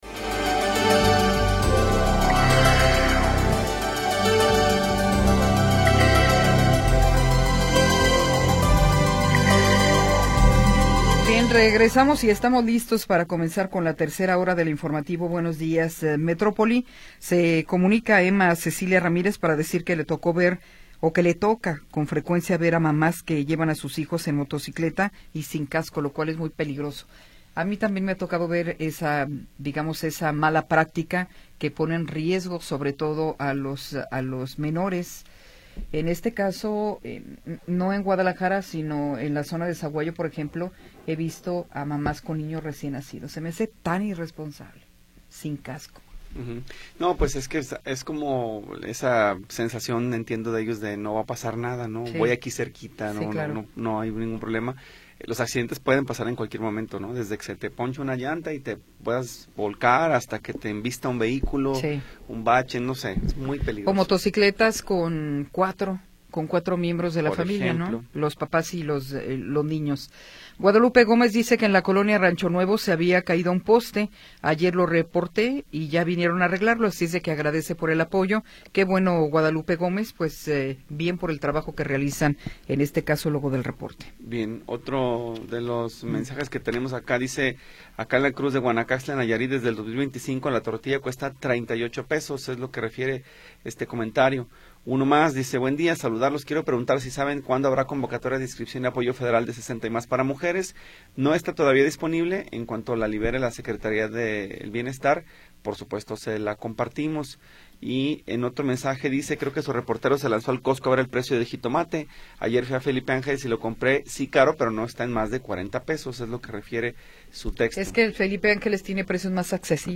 Tercera hora del programa transmitido el 16 de Abril de 2026.